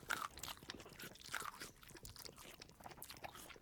Soundscape Overhaul / gamedata / sounds / monsters / rat / eat_0.ogg
eat_0.ogg